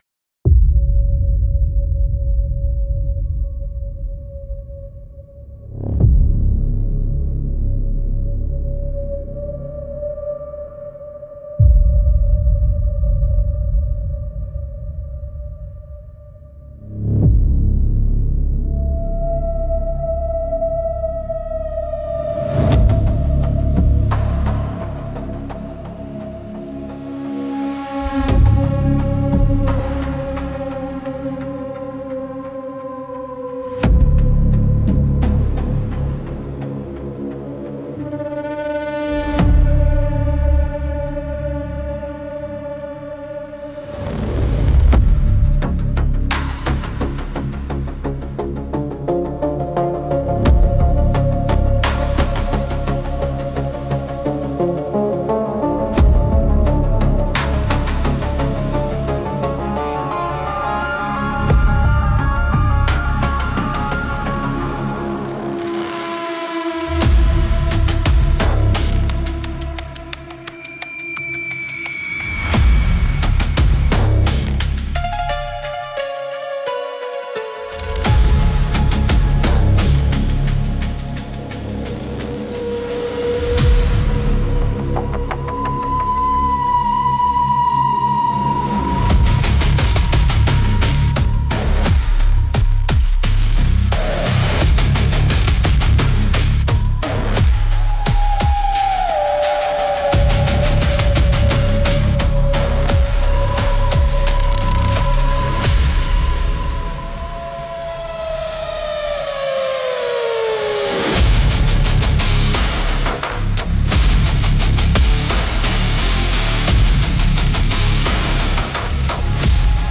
Instrumentals - The Ashes of a Silent Bell (1)